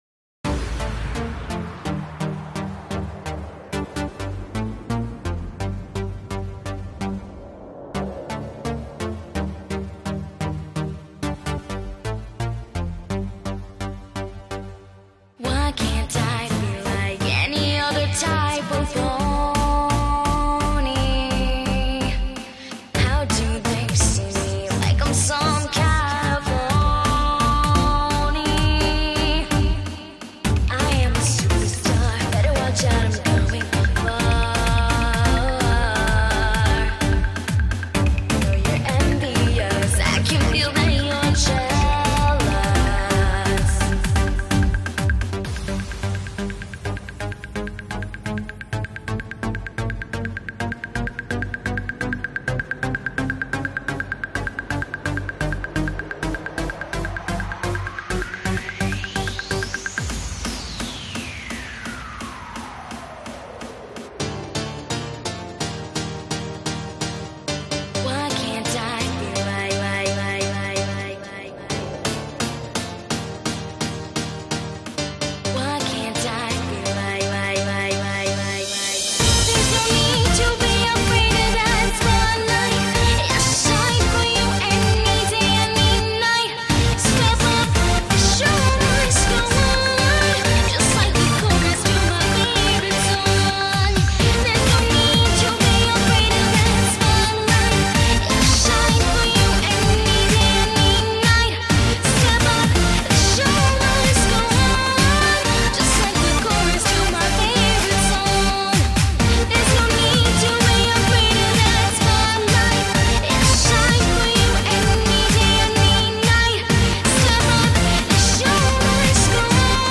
Amazing vocals